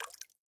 drip_water_cauldron8.ogg